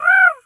pgs/Assets/Audio/Animal_Impersonations/crow_raven_call_squawk_03.wav at master
crow_raven_call_squawk_03.wav